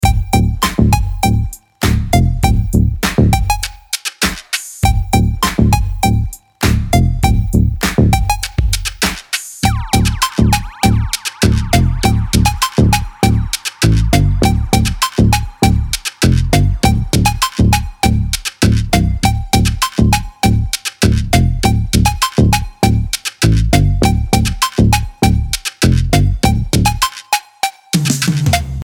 latin neo-soul tracks